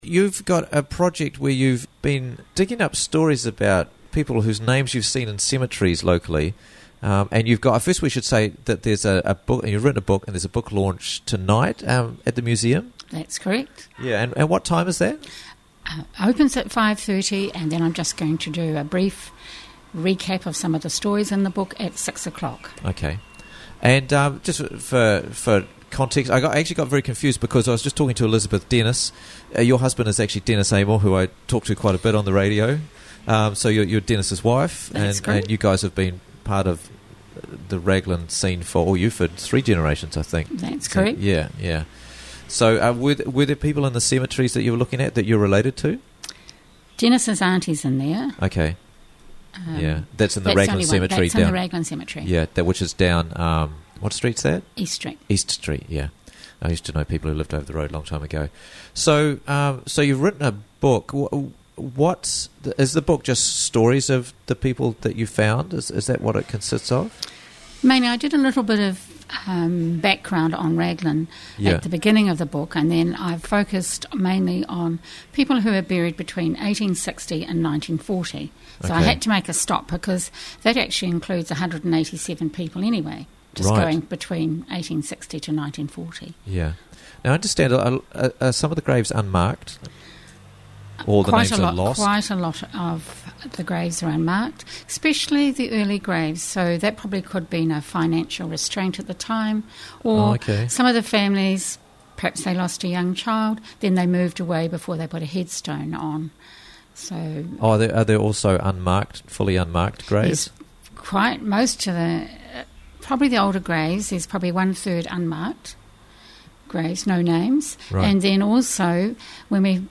Stories Behind The Headstones - Interviews from the Raglan Morning Show